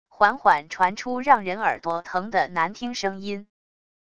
缓缓传出让人耳朵疼的难听声音wav音频